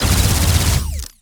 GUNAuto_Plasmid Machinegun C Burst Unstable_03_SFRMS_SCIWPNS.wav